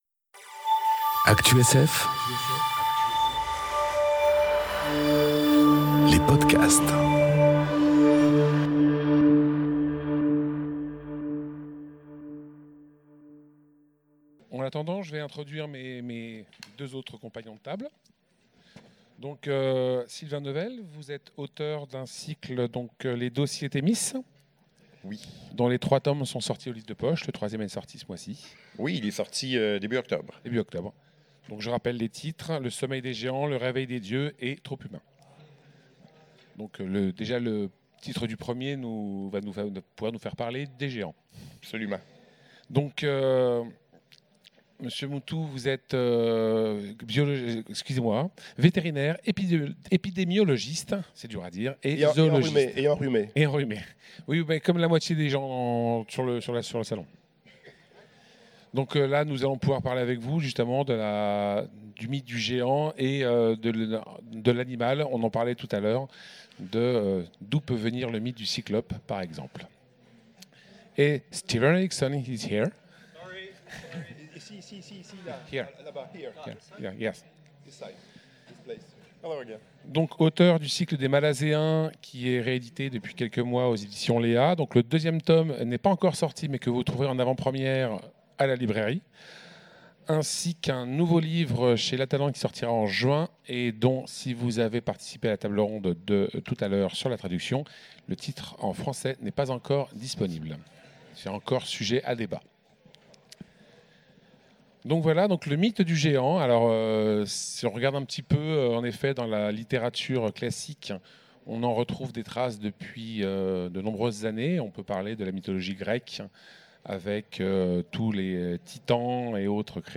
Conférence Le mythe du géant aux Utopiales 2018